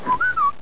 whistle2.mp3